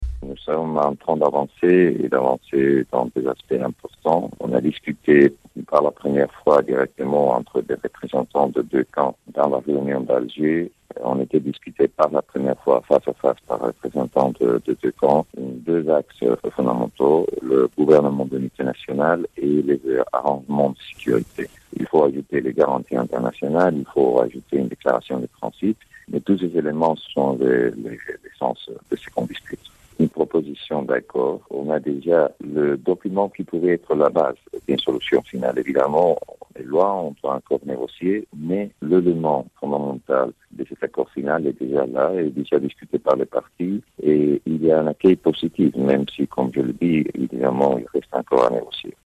Le chef de mission d'appui de l'ONU à la Libye, Bernardino Leon